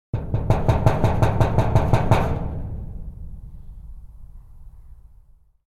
Creepy Knocking On Metal Door Sound Effect
Description: Creepy knocking on metal door sound effect. Eerie and suspenseful knocking on a metal door.
Scary sounds.
Creepy-knocking-on-metal-door-sound-effect.mp3